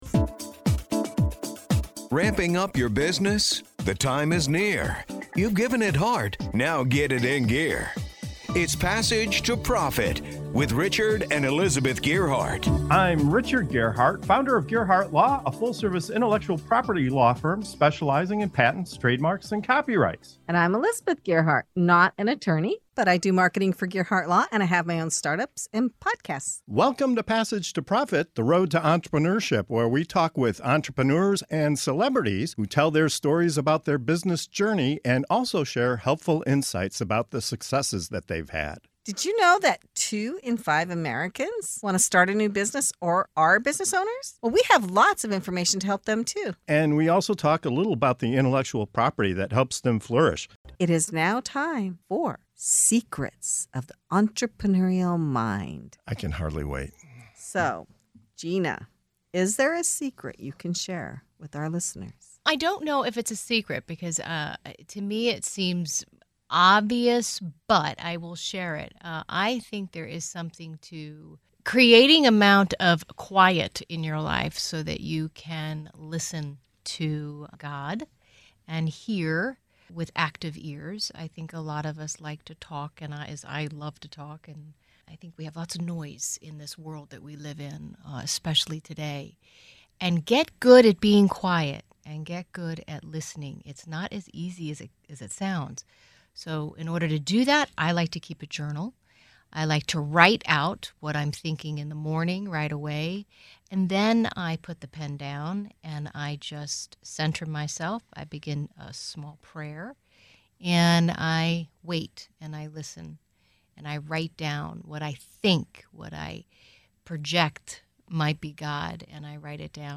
In this segment of “Secrets of the Entrepreneurial Mind” on the Passage to Profit Show, our panel reveals game-changing insights to elevate your business and mindset. Discover how quiet reflection can sharpen your vision, why taking messy action builds confidence, and the surprising psychology behind turning fence-sitters into loyal customers. Plus, hear inspiring advice on perseverance and the transformative power of finding the right coach.